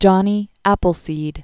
(jŏnē ăpəl-sēd)